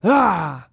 Male_Grunt_Attack_03.wav